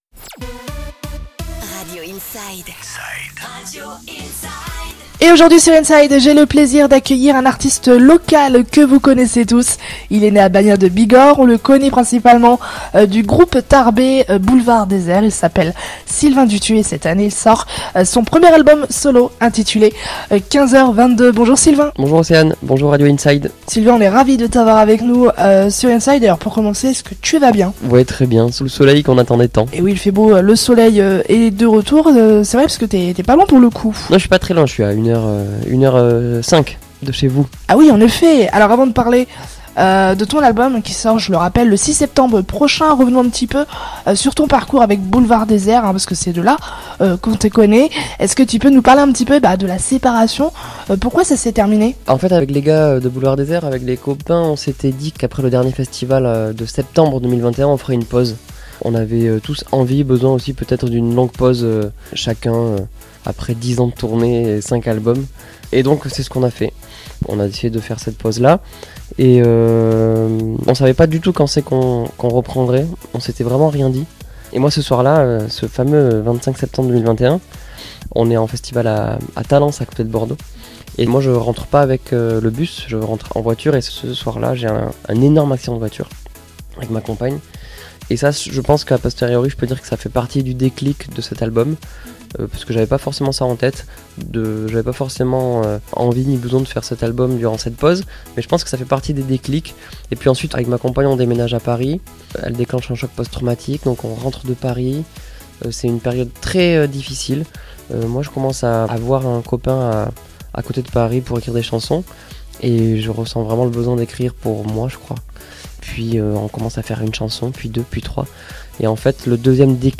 Interview intégrale